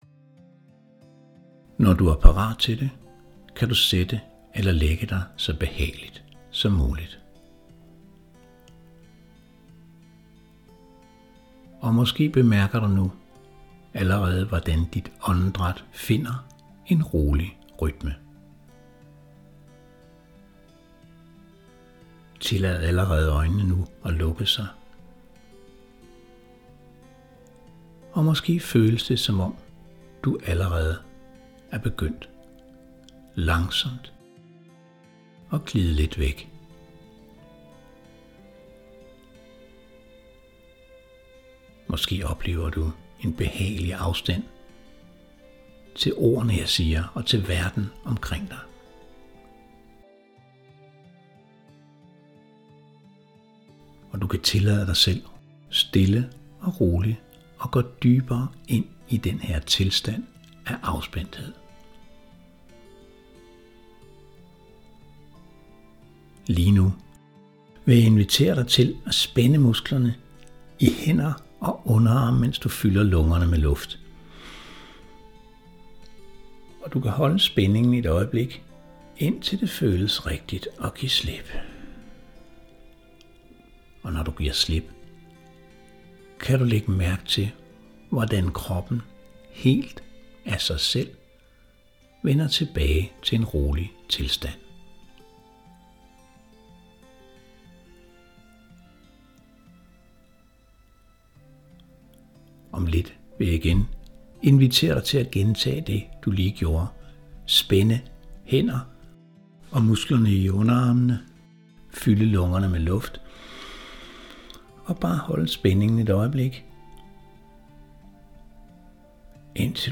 Lyt til 15 minutters fysisk afslapning.
Fysisk afslappelse er grundlaget for, at vigtige forandringer kan finde sted. Dette er en afslapningsfil, som handler om at træne systemet til at være i en tilstand af dyb ro.